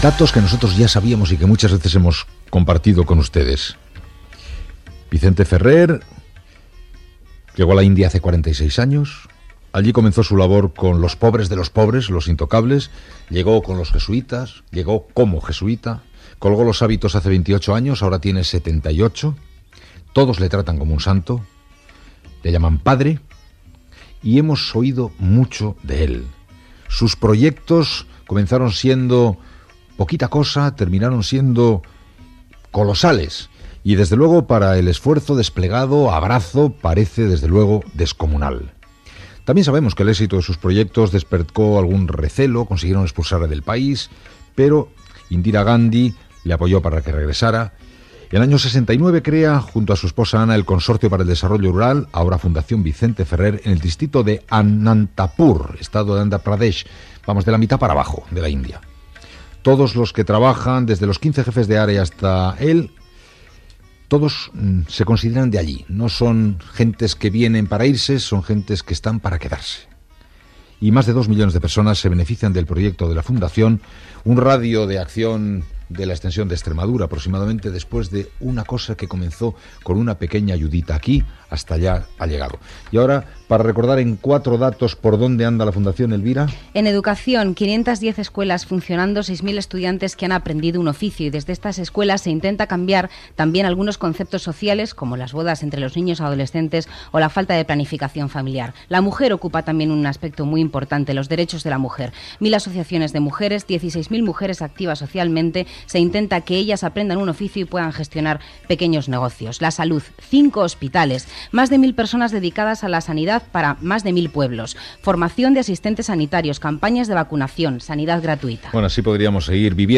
bf1fdf631b3dc5f7f9bc82ac9def5ff7abc74bd0.mp3 Títol Cadena SER Emissora Ràdio Barcelona Cadena SER Titularitat Privada estatal Nom programa Hoy por hoy Descripció Entrevista a Vicente Ferrer, ex jesuïta i president de la Fundació Vicente Ferrer. Parla de la seva activitat social i benèfica i de la lluita contra la sequera en un miler de pobles de l'Índia Presentador/a Gabilondo, Iñaki Data emissió 1998-05 Durada enregistrament 16:10 Notes Extret del canal d'iVoox Podium Podcast - Grandes entrevistas